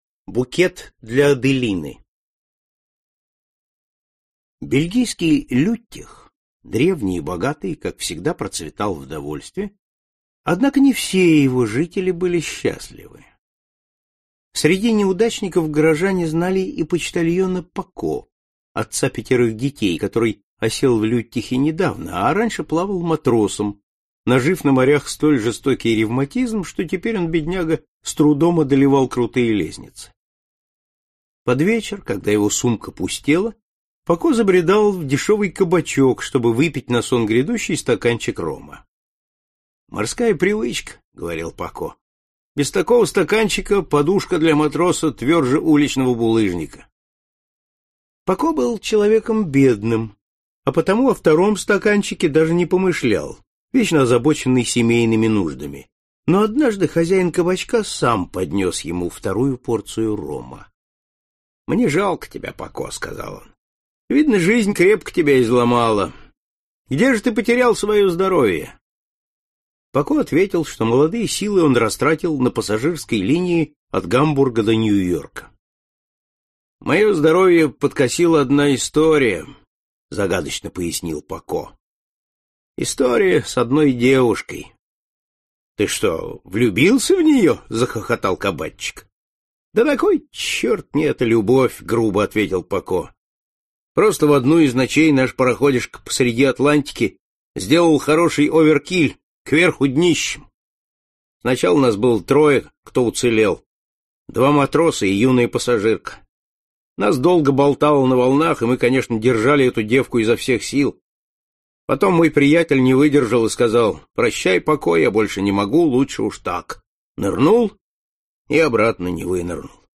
Аудиокнига Букет для Аделины | Библиотека аудиокниг
Aудиокнига Букет для Аделины Автор Валентин Пикуль Читает аудиокнигу Сергей Чонишвили.